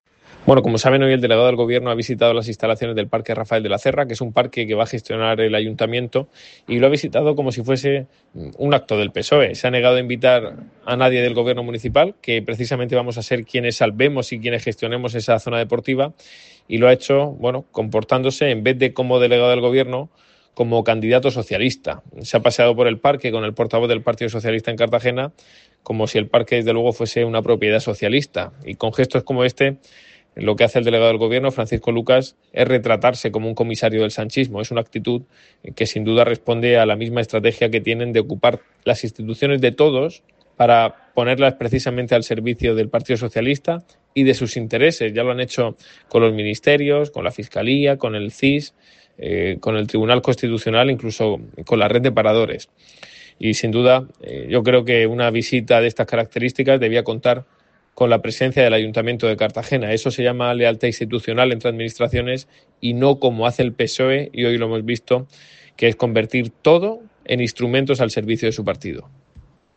Ignacio Jáudenes, portavoz del Grupo Municipal Popular